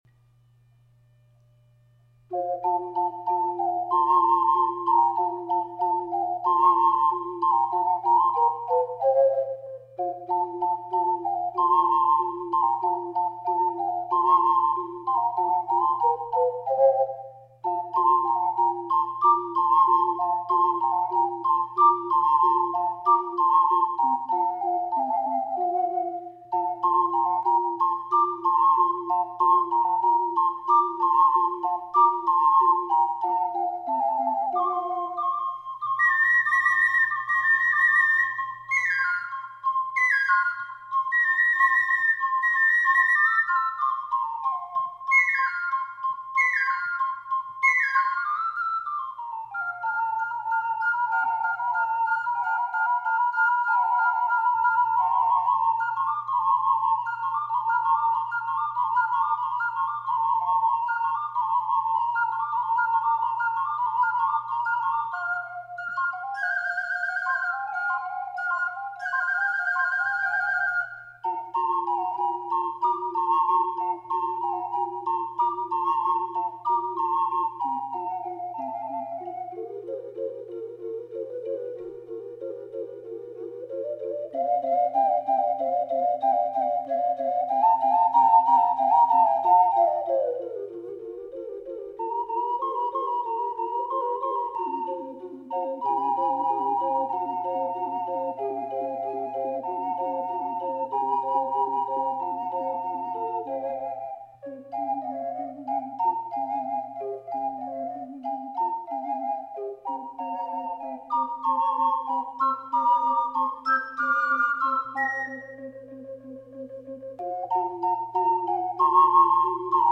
楽譜通りの高さです。
◆4ｔｈは、ＢＣ管だけですが、低音ファはオクターブ上げて吹きました。